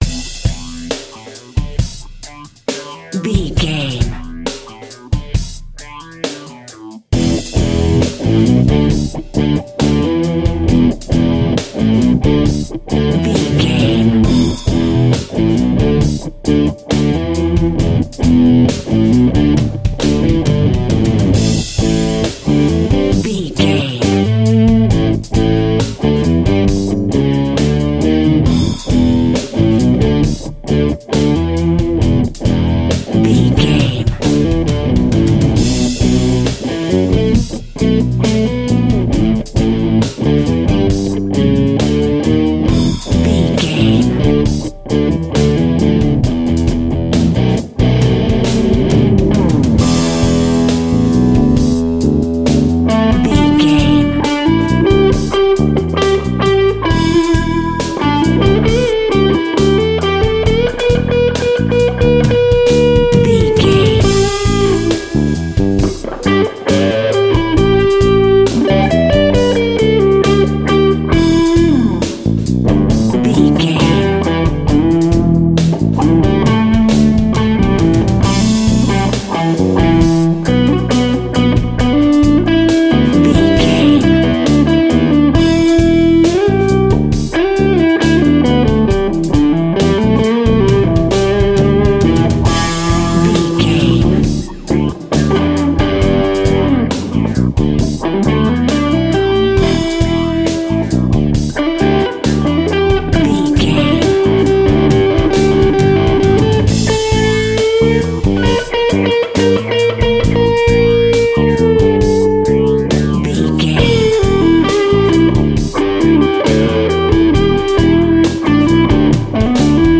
Epic / Action
Aeolian/Minor
hard rock
heavy metal
blues rock
instrumentals
Rock Bass
heavy drums
distorted guitars
hammond organ